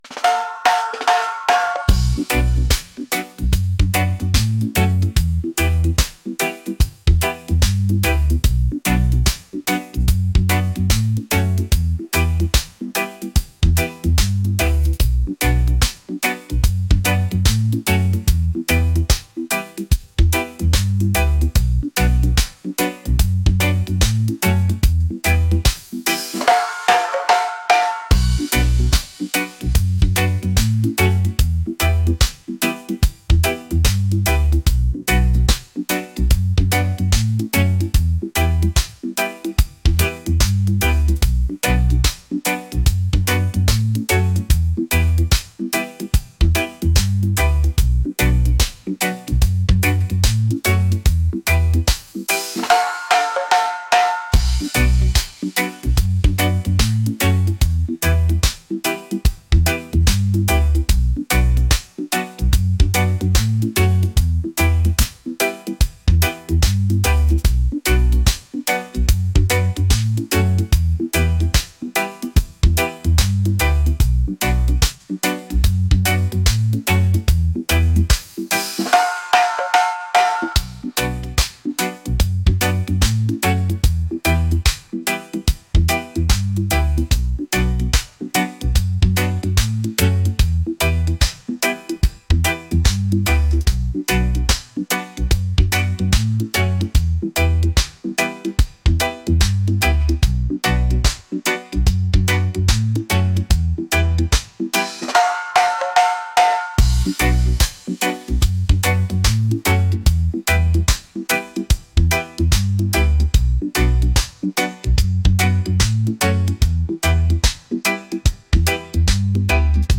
laid-back | reggae | groovy